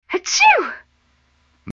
sneeze.wav